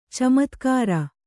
♪ camatkāra